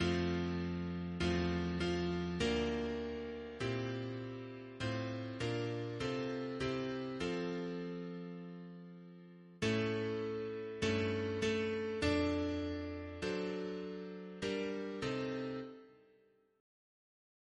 CCP: Chant sampler
Triple chant in F Composer: John Naylor (1838-1897), Organist of York Minster Reference psalters: ACB: 27; ACP: 169; RSCM: 218